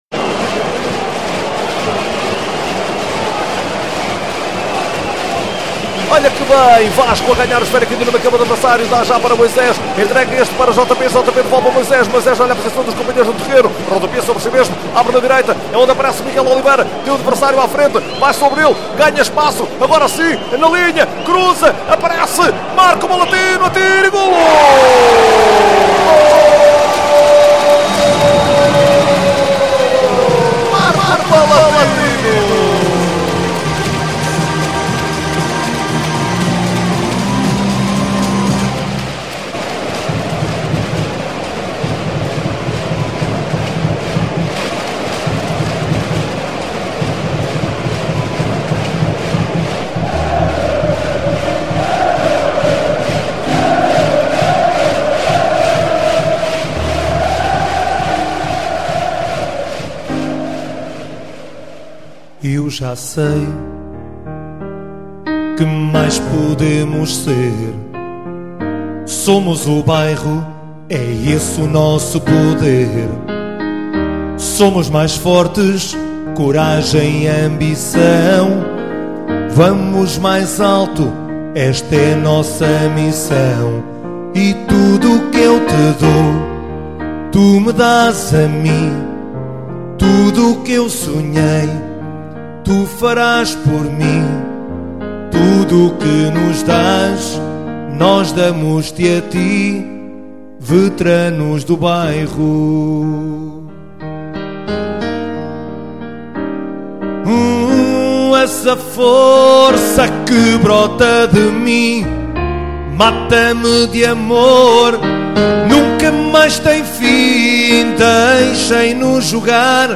Hino com relato